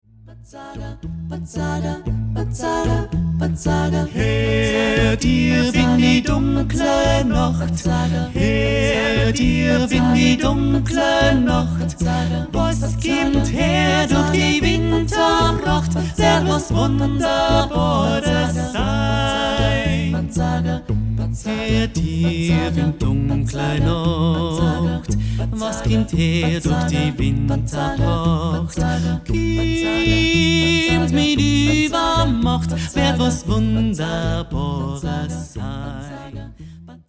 A cappella unter’m Weihnachtsbaum